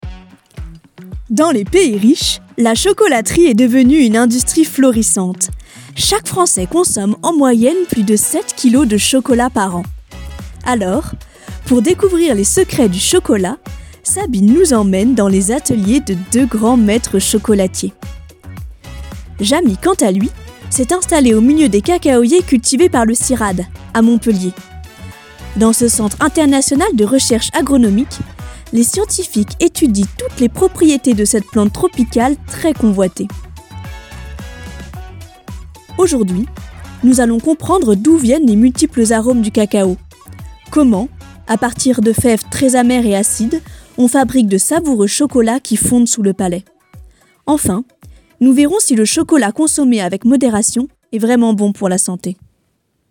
Voix off
maquette documentaire